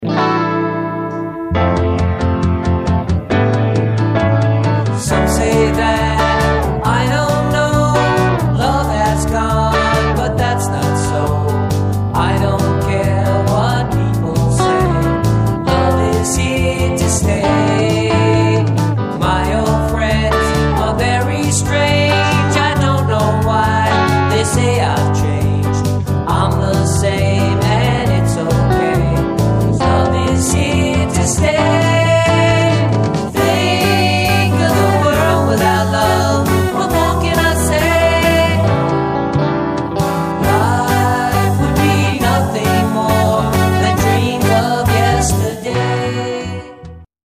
POWERPOP